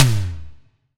Index of /90_sSampleCDs/EdgeSounds - Drum Mashines VOL-1/SIMMONSDRUMS